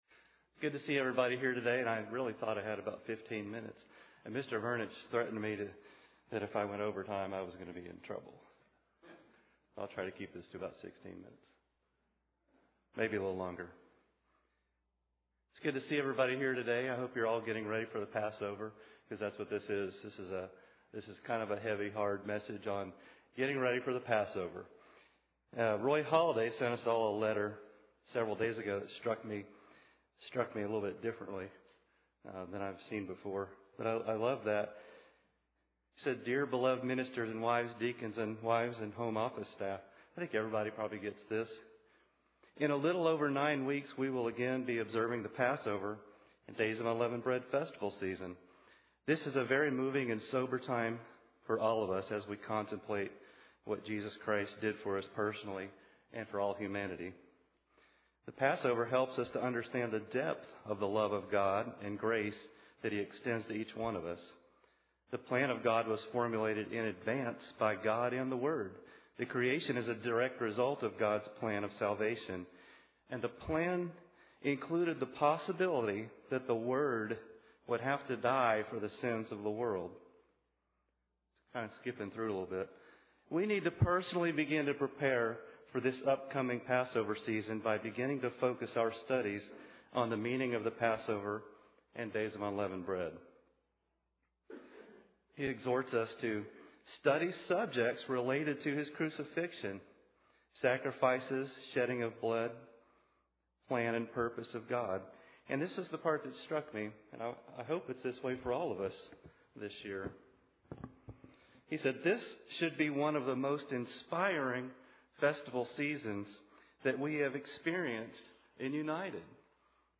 An instructive sermon on how to prepare for the Passover.
Given in Nashville, TN